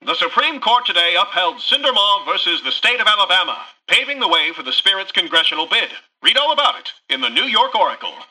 Newscaster_headline_20.mp3